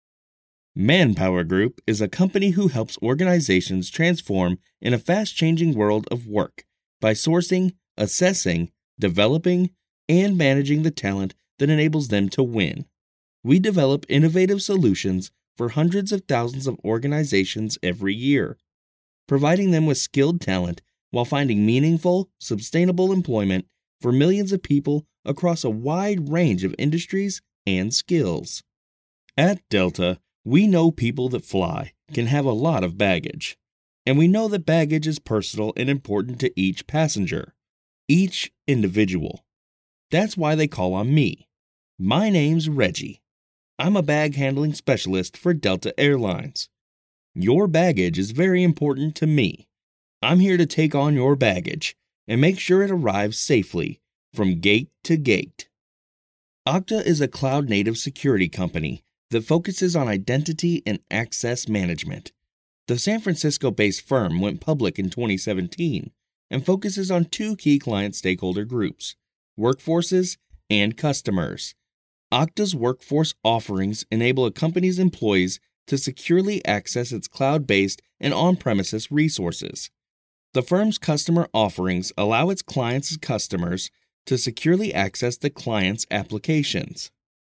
I have worked with some great clients such as WalMart, SNHU, Oil Changers INC, ETC. I bring professional Studio quality sound to you project.
Corporate demo
I specialize in strong, deep, guy next door, conversational, confident, friendly, and professional voice overs. i can provide a free sample if needed.
My experiences in vo include: • e learning • commercial • radio • tv • internet video • telephony • character • audio book • narration I use a rode nt microphone microsoft computer adobe audition daw.
corporate demo.mp3